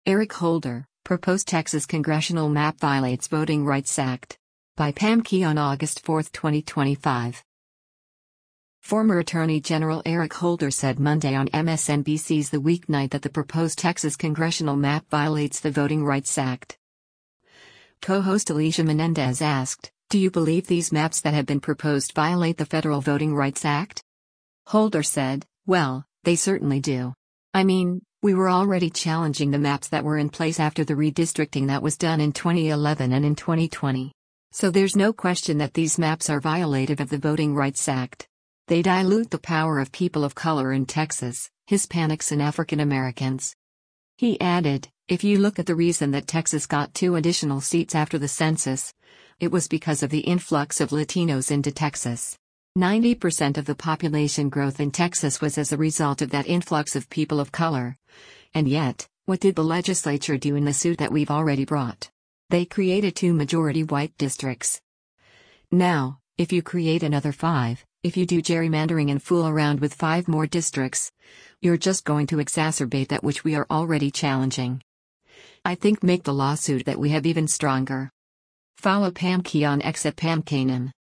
Former Attorney General Eric Holder said Monday on MSNBC’s “The Weeknight” that the proposed Texas congressional map violates the Voting Rights Act.